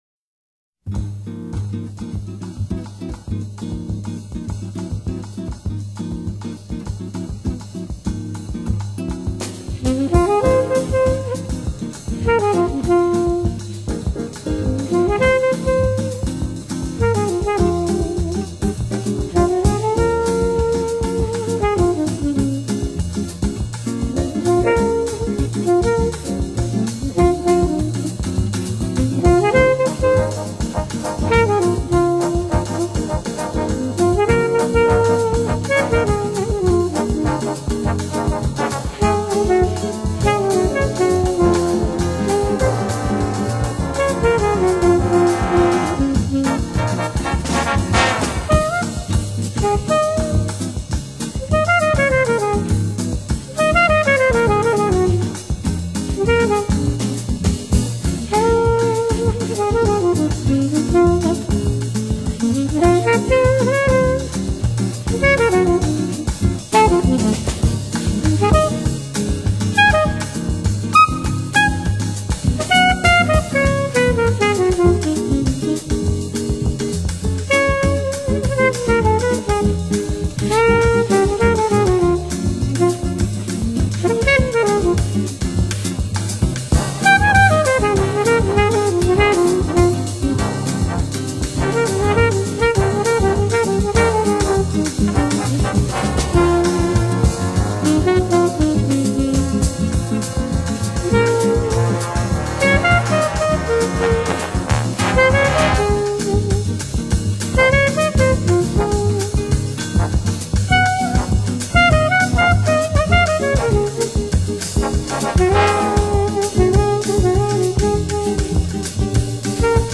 Jazz, Vocal Jazz